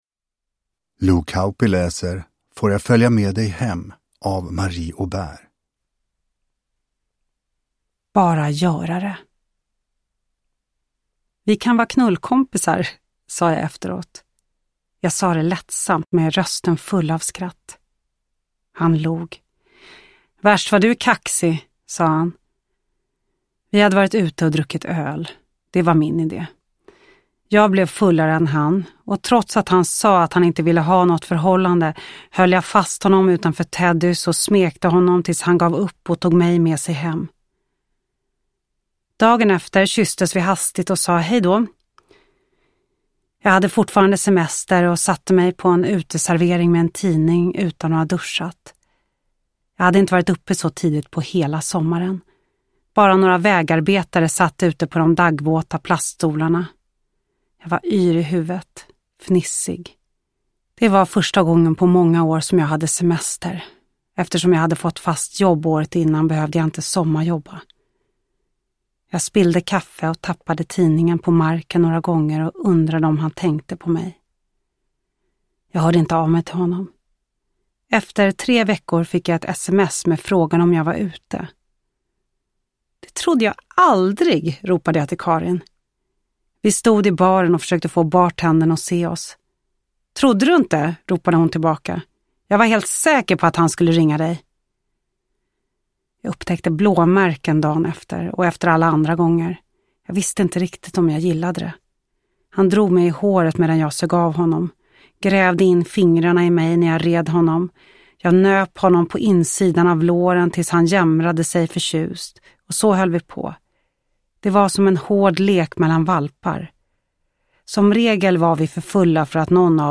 Får jag följa med dig hem – Ljudbok – Laddas ner
Uppläsare: Lo Kauppi